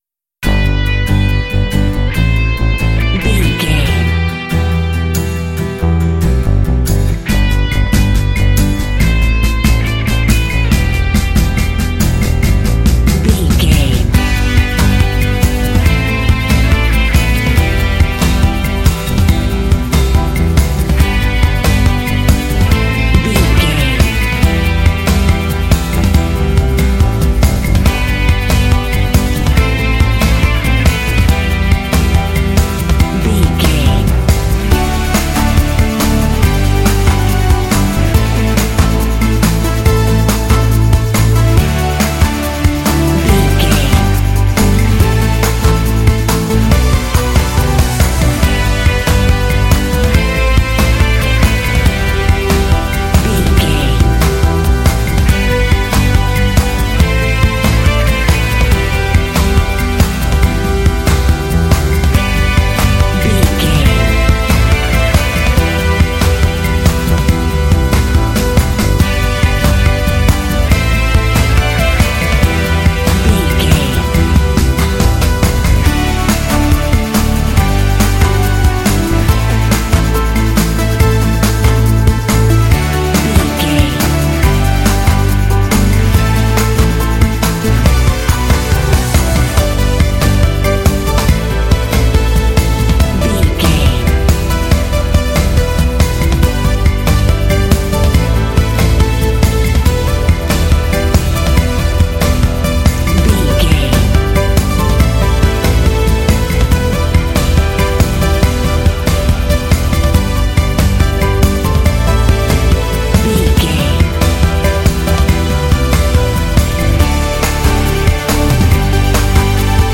Ideal for action and sport games.
Epic / Action
Ionian/Major
Fast
confident
positive
uplifting
energetic
acoustic guitar
electric guitar
bass guitar
drums
piano
synthesiser
strings
contemporary underscore
rock